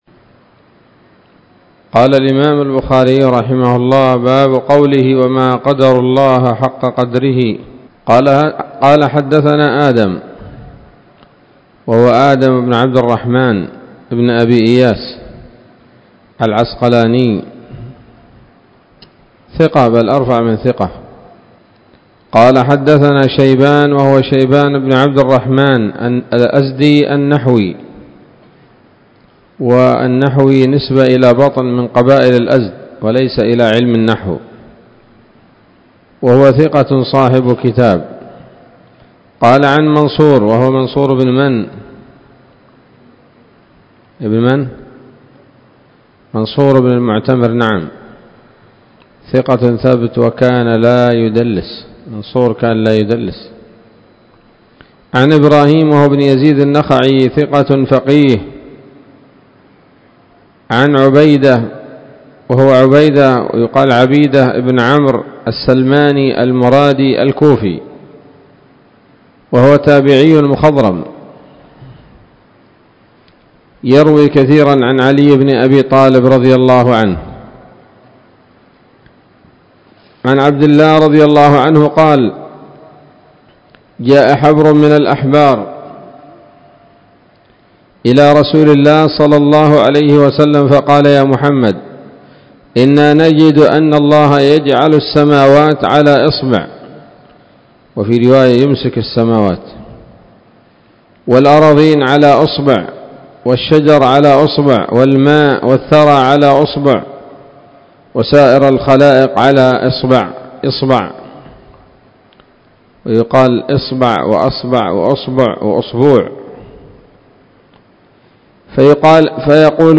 الدرس الثامن عشر بعد المائتين من كتاب التفسير من صحيح الإمام البخاري